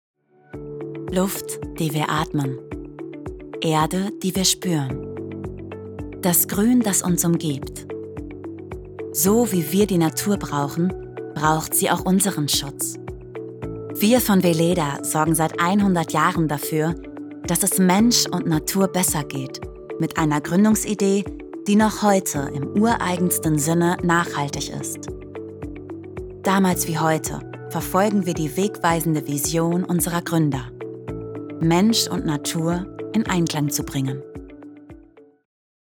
Sprecherin
Sprechproben